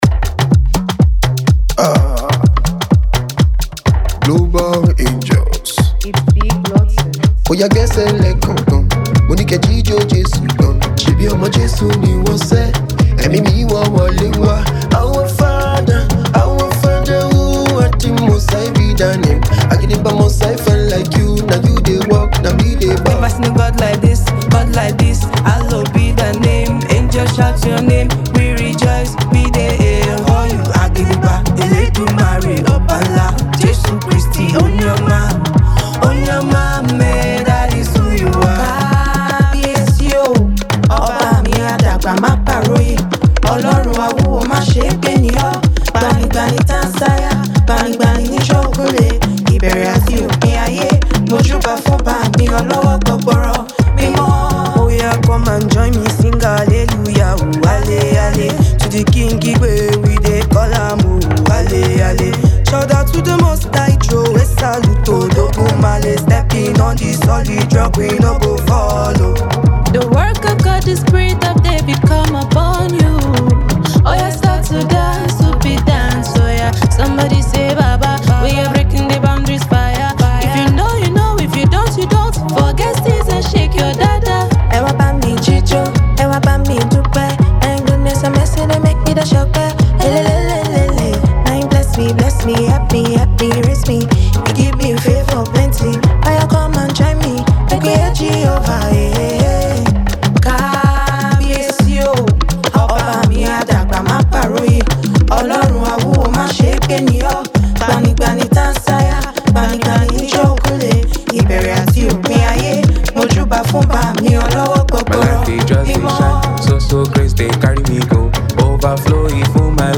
captivating rhythms, powerful vocals, and inspiring lyrics